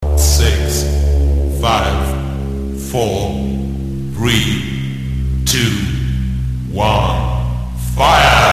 Kermis geluid 654321 Aftellen
Categorie: Geluidseffecten
geluidseffecten, kermis geluiden